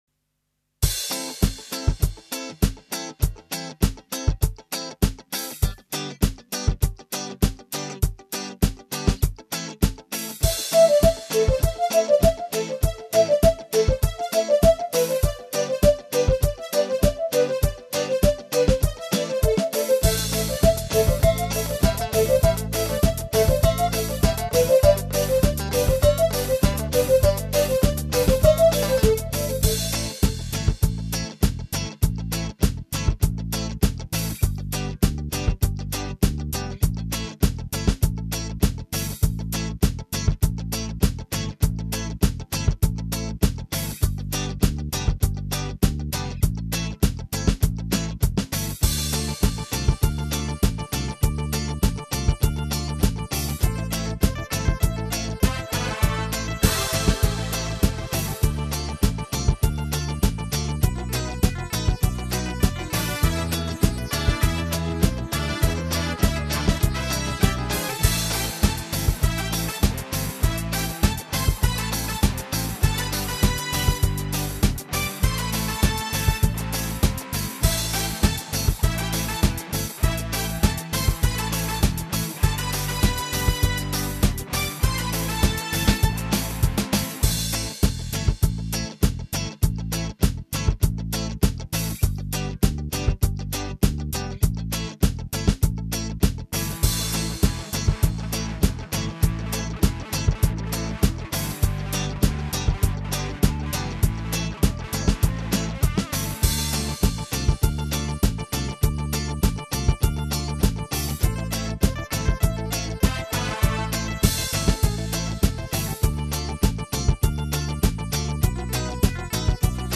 минусовка / караоке